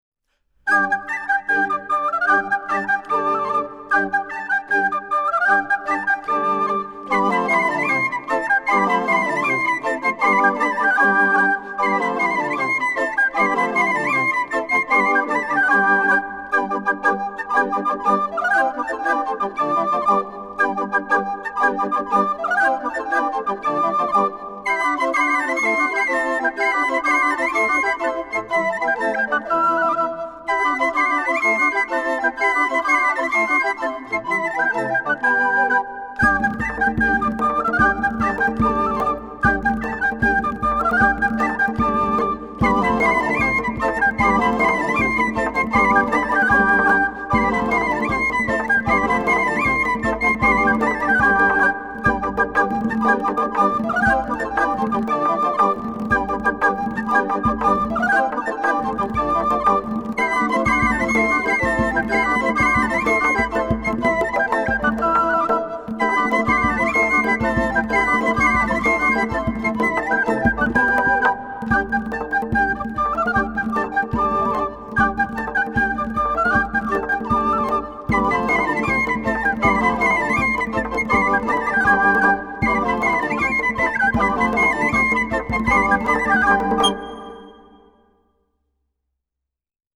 recorders
zarb, daf & djembé
palmas & finger cymbals
microphones: Neumann, AKG, Schoeps